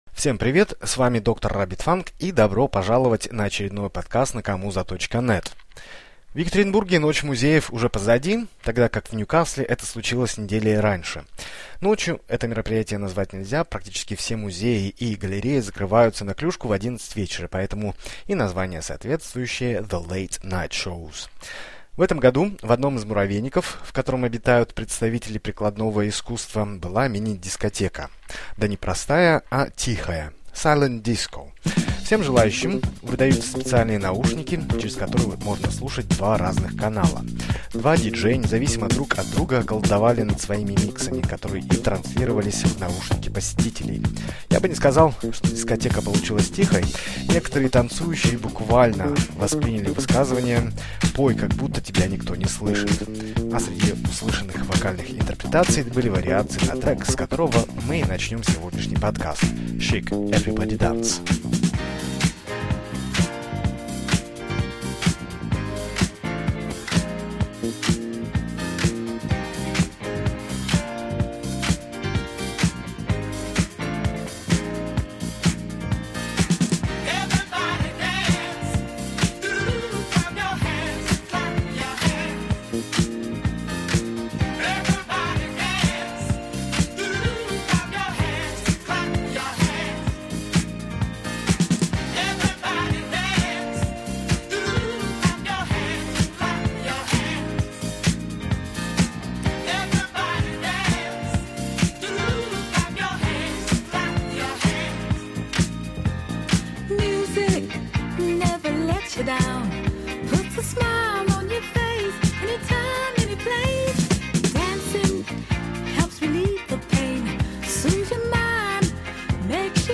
Музыка, которая воспитала в нас все танцевально прекрасное.